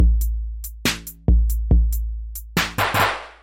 超级微型Dubstep鼓1 140 BPM
描述：电音鼓循环，适用于dubstep音乐。踢腿小鼓 高帽
Tag: 140 bpm Dubstep Loops Drum Loops 590.80 KB wav Key : Unknown